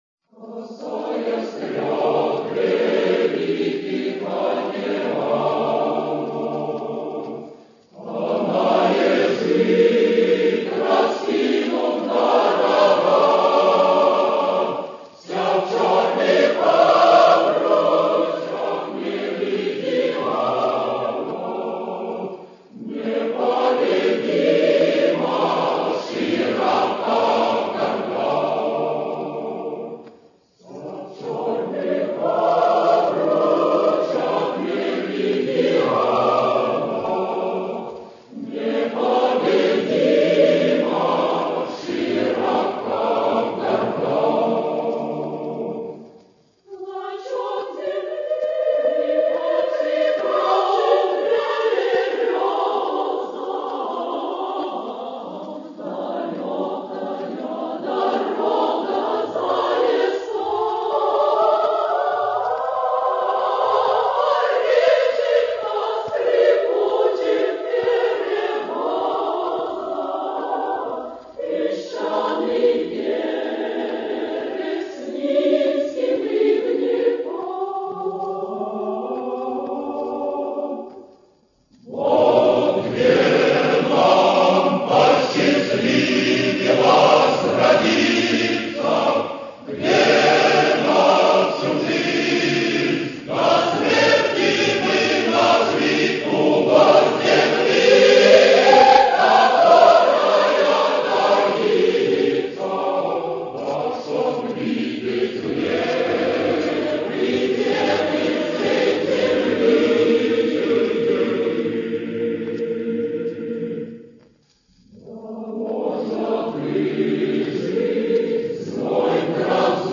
Послевоенное исполнение
Пластинка из первых долгоиграющих серий №4817.